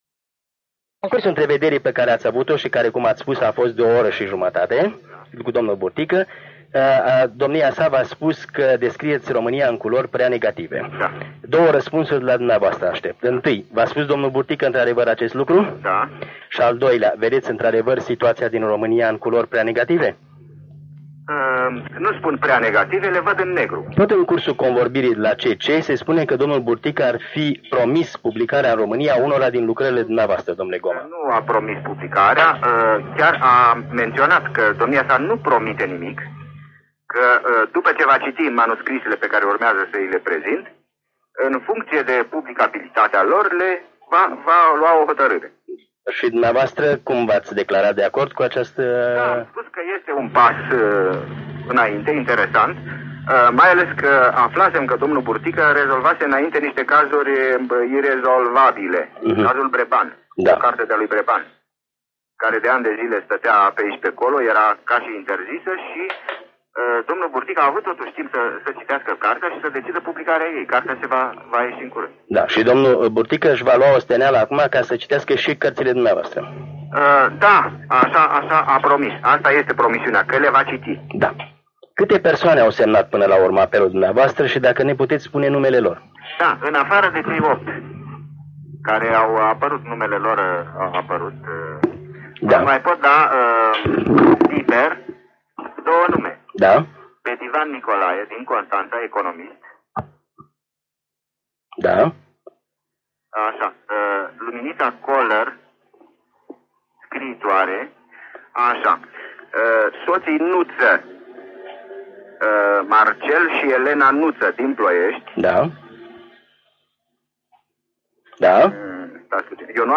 Interviu realizat la București prin telefon în 23 februarie 1977.